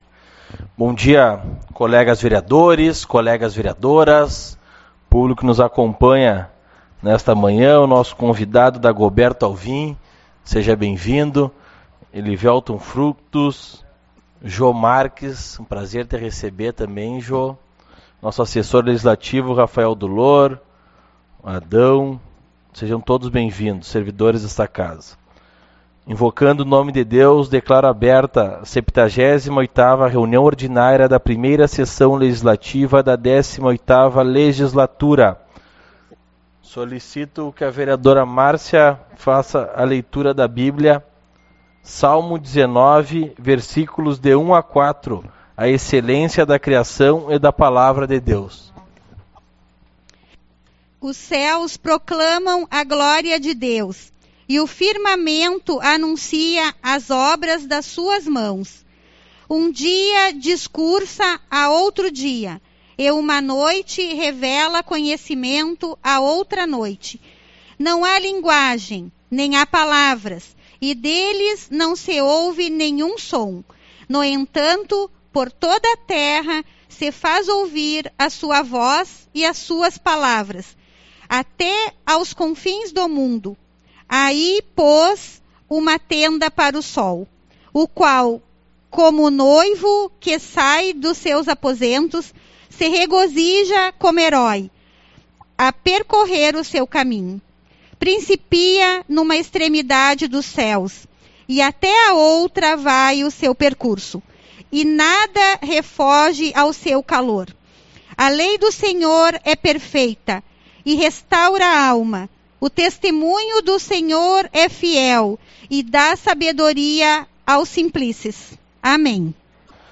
25/11 - Reunião Ordinária